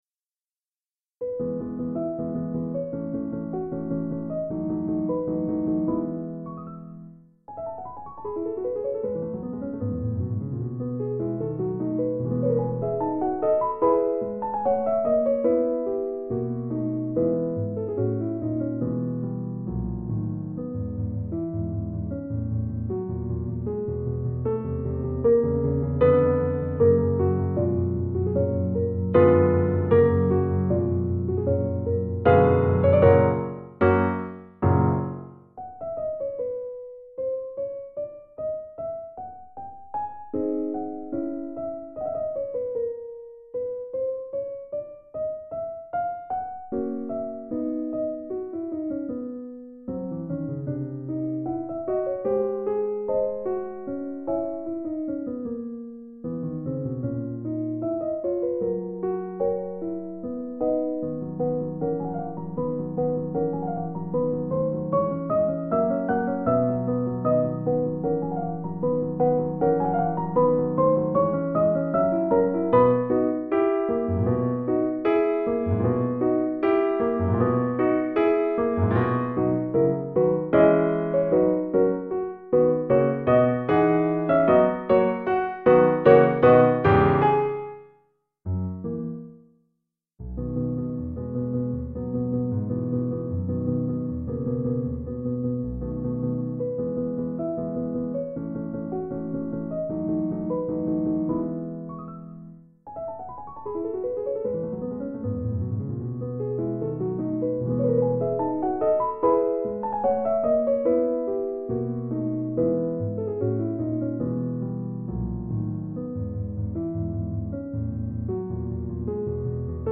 Sunday Evening Concert (9): Beethoven – Piano Sonata No.9 in E, Op.14, No.1